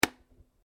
button_click_sfx.mp3